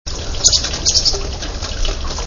Black-capped Chickadee
Black-capped Chickadee in an argument with a Titmouse at the feeder in heavy rain (9kb)